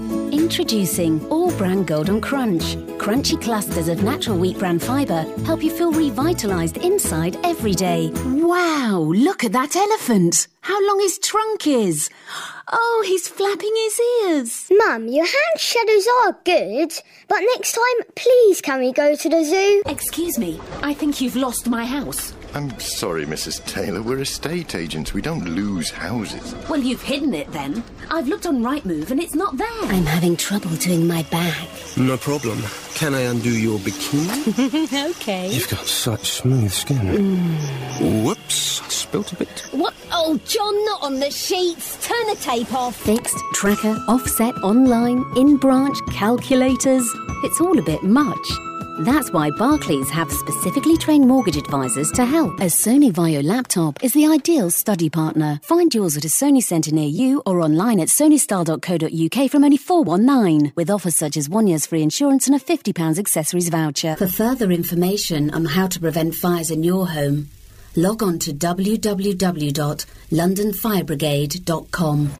Commercial Showreel